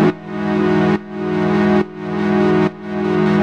Index of /musicradar/sidechained-samples/140bpm
GnS_Pad-MiscB1:2_140-E.wav